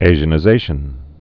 (āzhə-nĭ-zāshən)